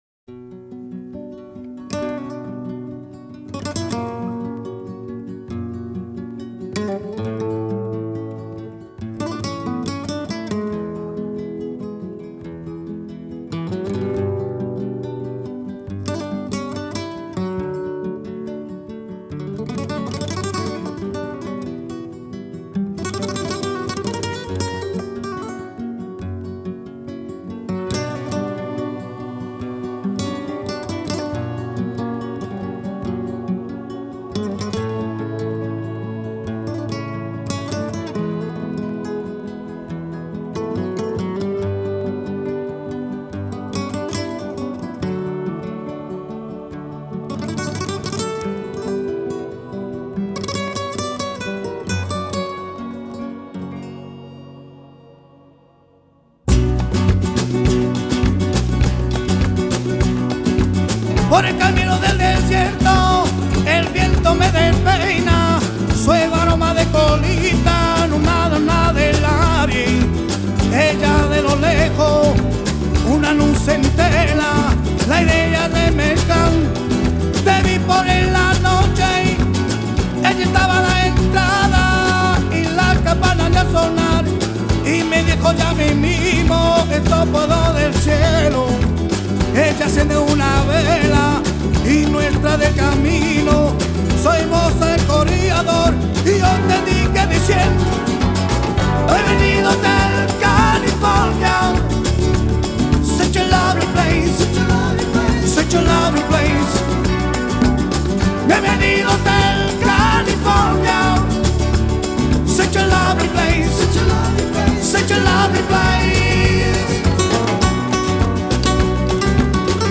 Genre: Flamengo